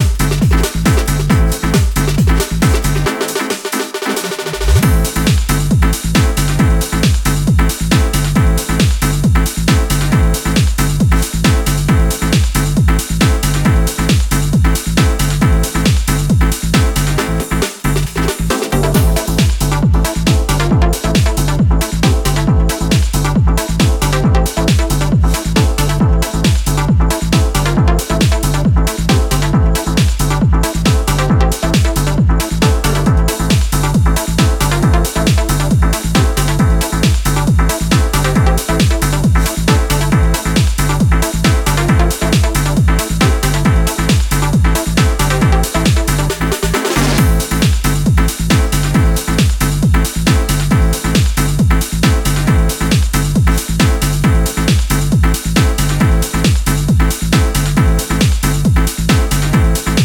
Genre Progressive House , Rave